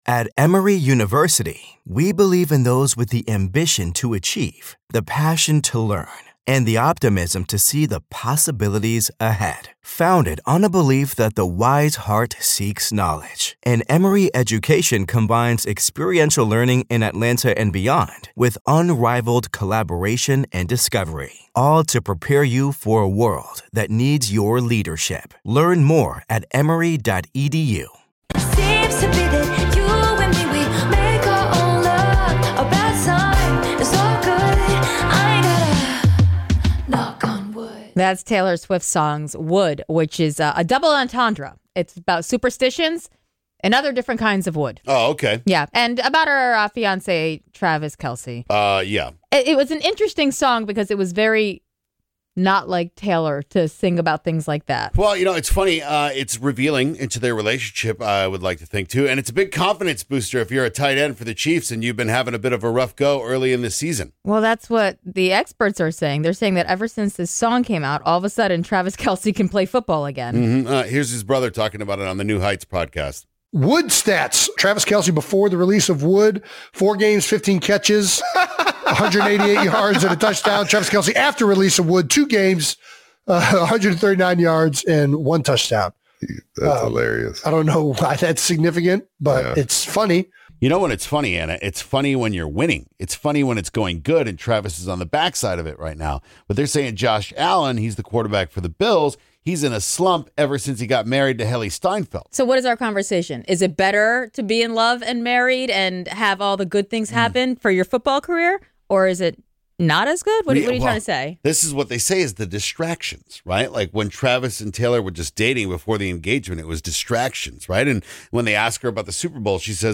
And they hear from listeners calls about what they are fans of!